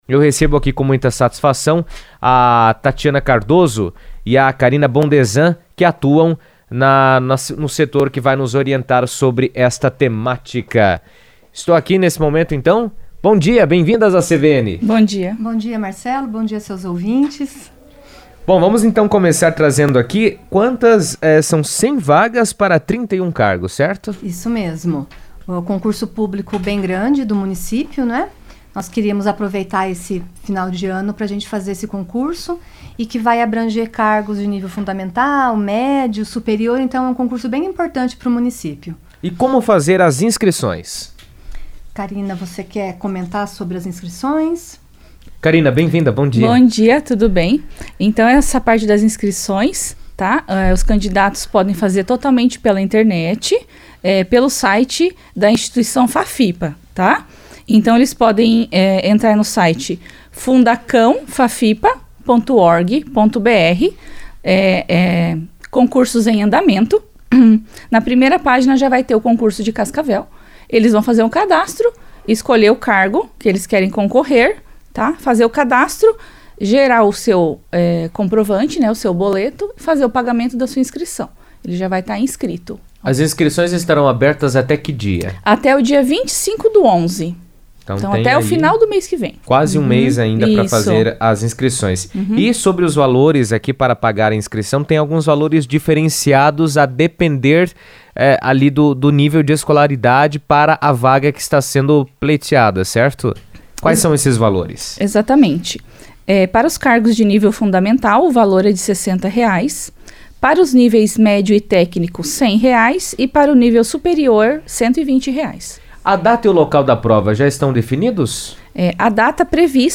A Prefeitura de Cascavel abriu um concurso público com mais de 100 vagas em diversos setores, oferecendo oportunidades para diferentes níveis de escolaridade. Em entrevista à CBN